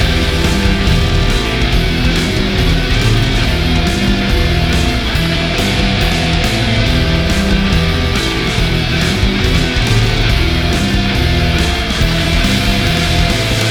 Warning: This artwork incorporates iAMF frequency elements intended for subconscious conditioning.
These ‘Infinity L00p’ soundscapes, crafted with precision, resonate with the listener’s subconscious, creating an auditory experience that lingers long after the final note.